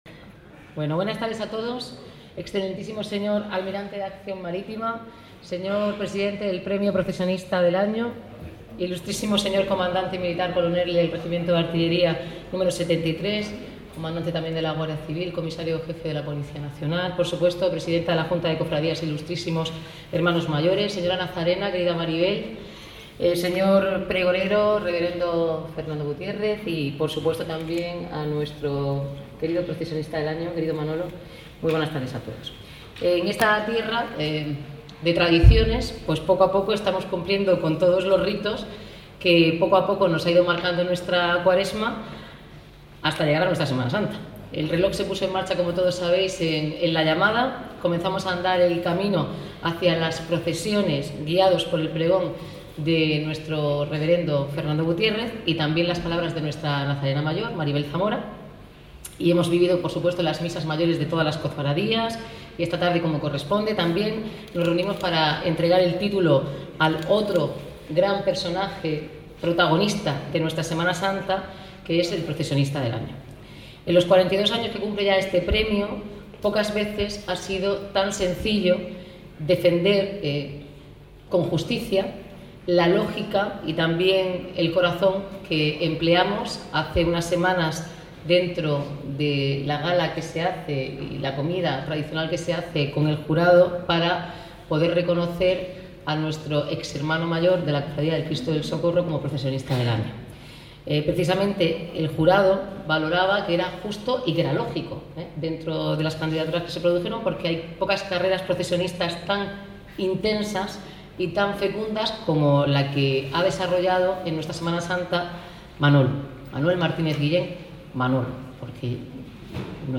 El Palacio Consistorial ha sido este viernes escenario del acto de entrega del galardón, ante la presencia de numerosas autoridades civiles y militares, así como familiares y amigos del homenajeado